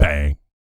BS BANG 02-L.wav